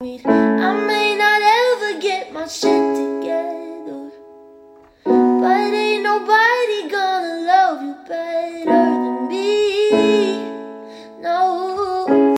Ugh Crying Sound Effects Free Download